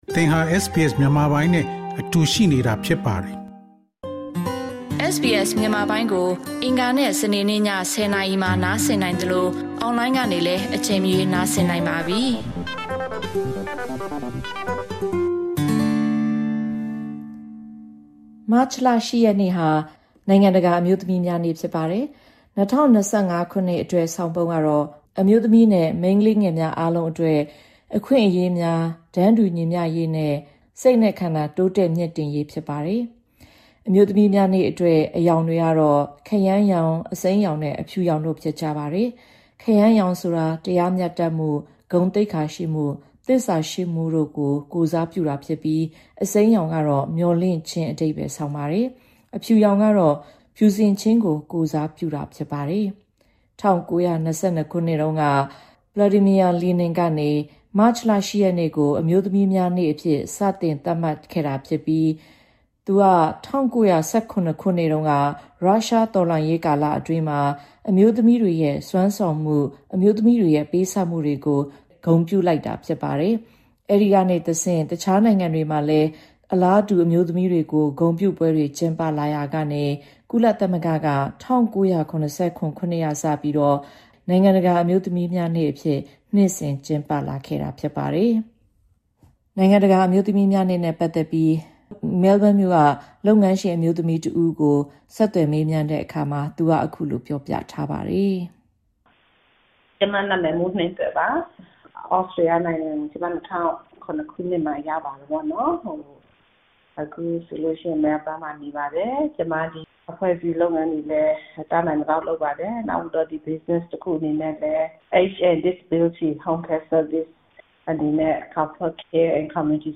အပြည်ပြည်ဆိုင်ရာ အမျိုးသမီးများနေ့တွင် မြန်မာအမျိုးသမီးများအားမေးမြန်းခန်း။